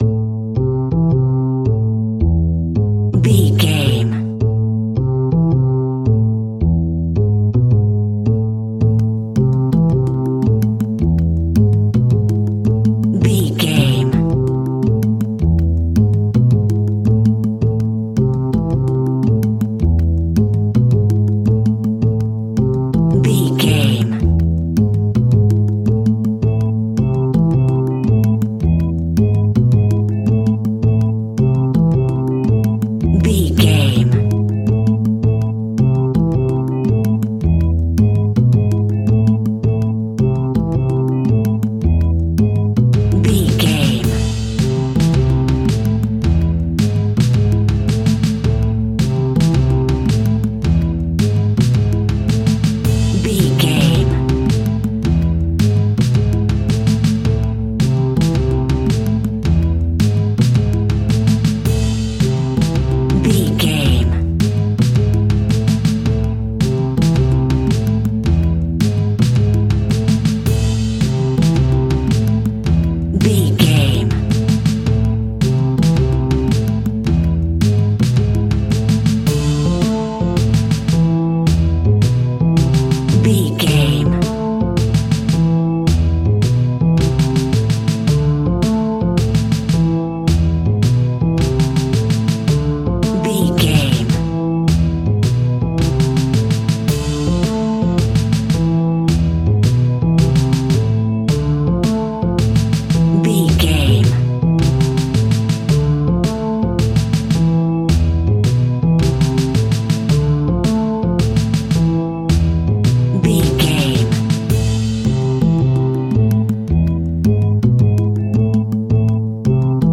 Aeolian/Minor
scary
ominous
haunting
eerie
playful
double bass
electric organ
drums
spooky
horror music